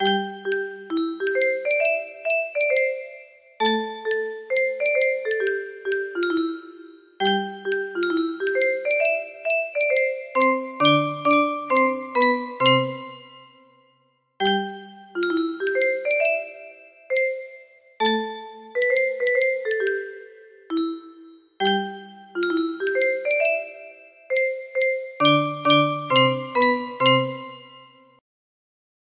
John Brown Song (correct 30 note) music box melody